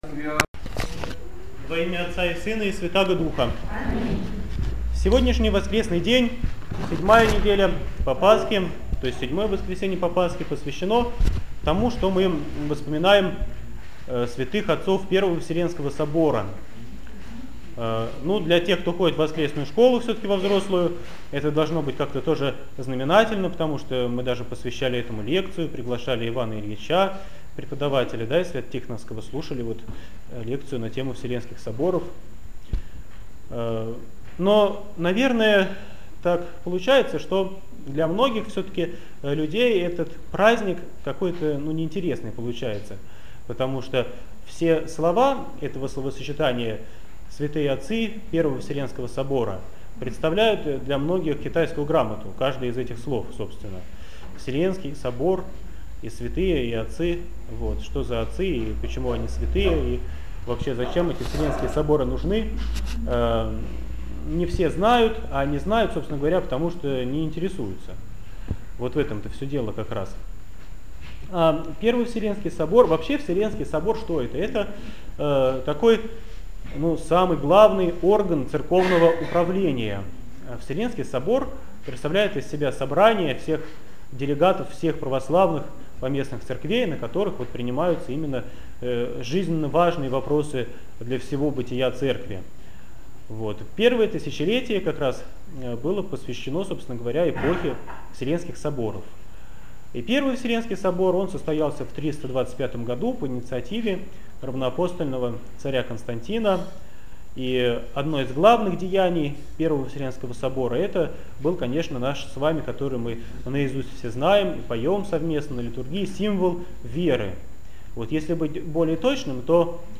БОГОЛЮБСКИЙ ХРАМ ПОСЕЛОК ДУБРОВСКИЙ - Проповедь в Неделю отцов Первого Вселенского Собора 2013
БОГОЛЮБСКИЙ ХРАМ ПОСЕЛОК ДУБРОВСКИЙ